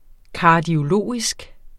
Udtale [ ˌkɑːdioˈloˀisg ] eller [ kɑdjo- ]